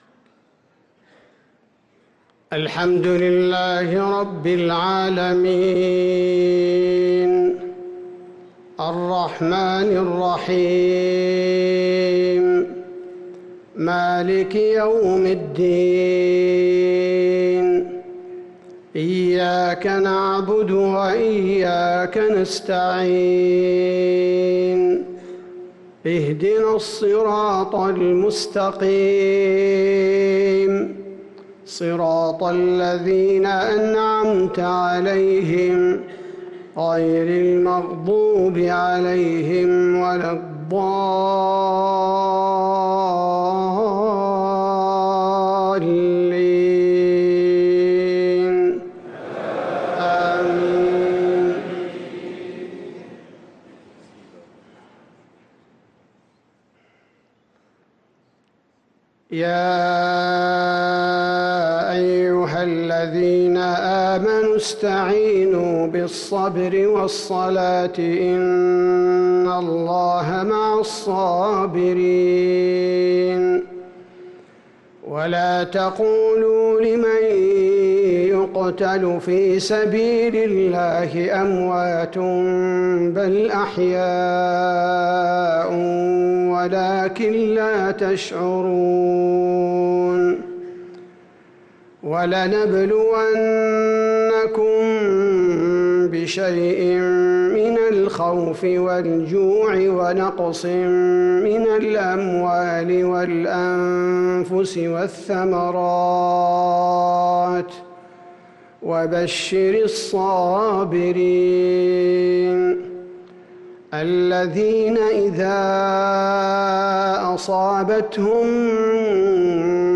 صلاة المغرب للقارئ عبدالباري الثبيتي 3 ربيع الأول 1445 هـ
تِلَاوَات الْحَرَمَيْن .